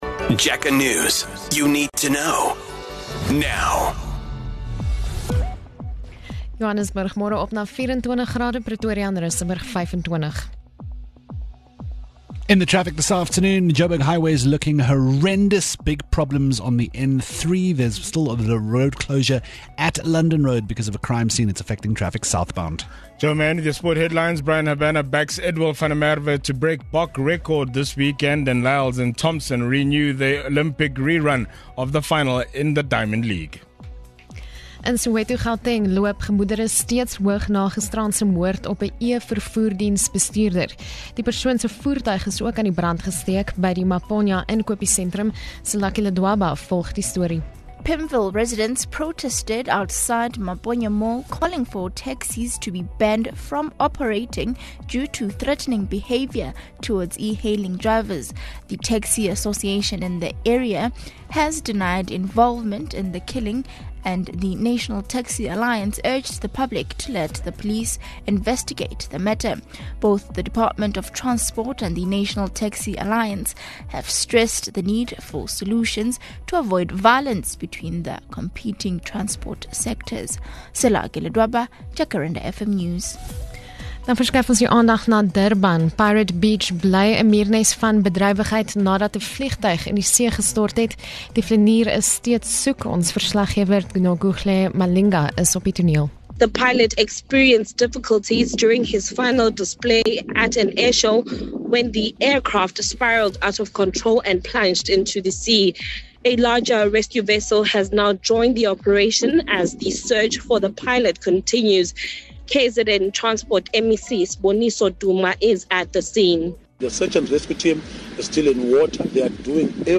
Jacaranda FM News Bulletins